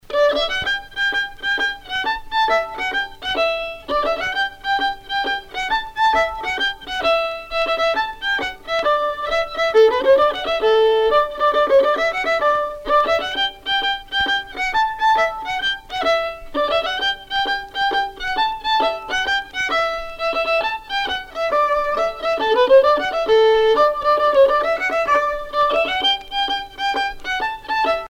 Rondes enfantines à baisers ou mariages
danse : ronde : boulangère ;
Pièce musicale éditée